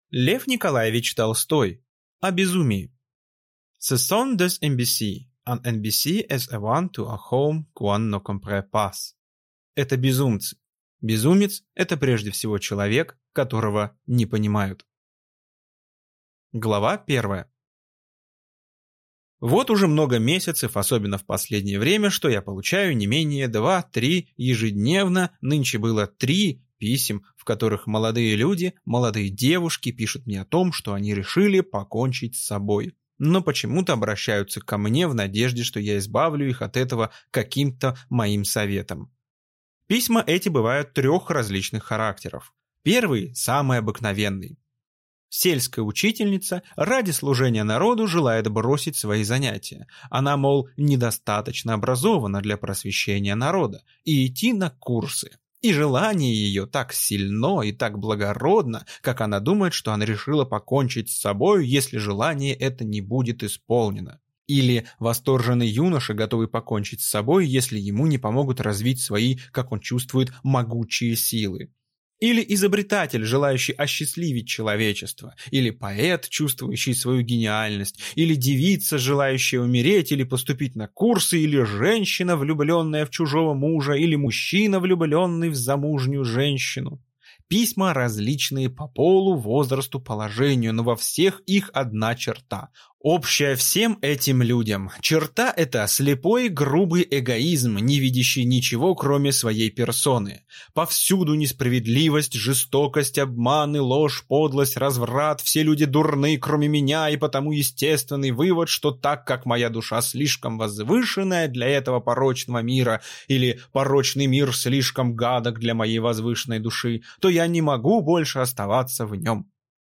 Аудиокнига О безумии | Библиотека аудиокниг